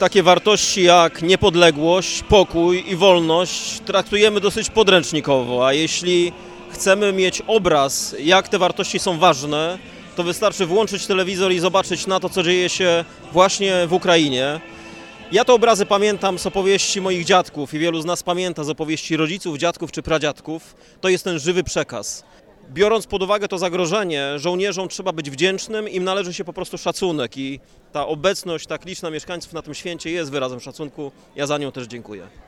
W czasie uroczystości miało miejsce także przyznanie odznaczeń dla zasłużonych żołnierzy, a także przemawiał Prezydent Stargardu Rafał Zając, który podkreślał, jaką wagę w dzisiejszych czasach ma dla nas, nawet w wymiarze lokalnym, wojsko polskie.